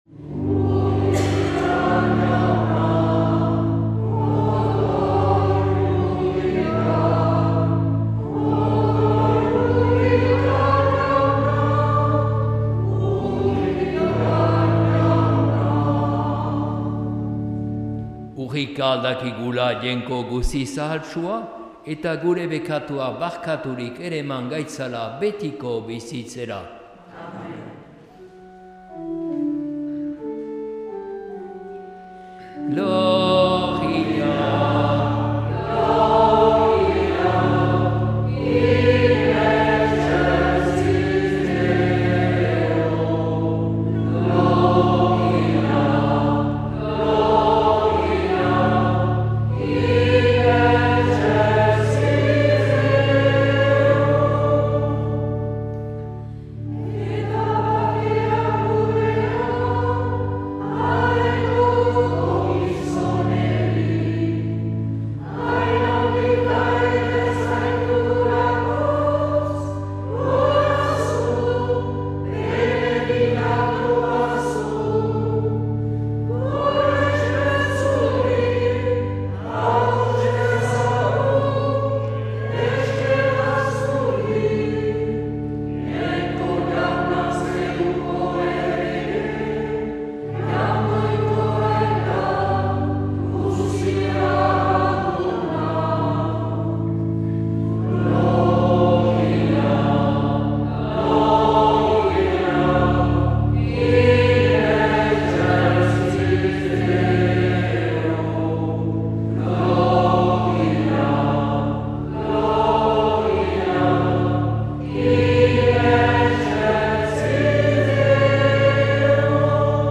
2022-02-13 Urteko 6. Igandea C - Donazaharre